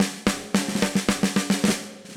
Index of /musicradar/80s-heat-samples/110bpm
AM_MiliSnareB_110-01.wav